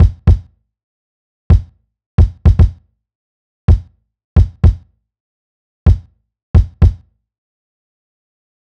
Unison Funk - 7 - 110bpm - Kick.wav